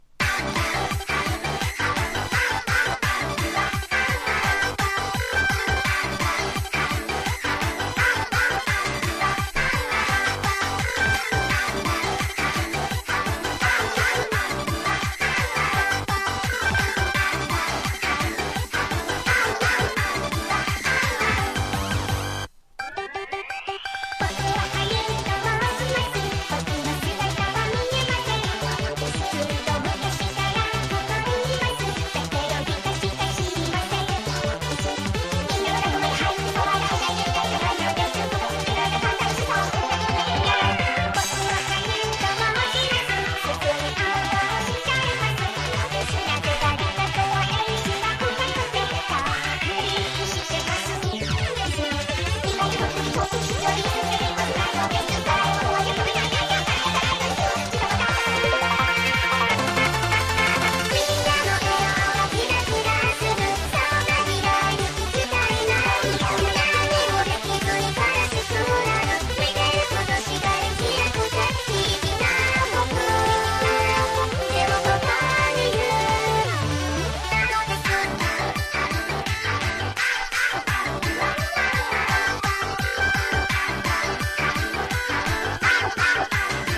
# POP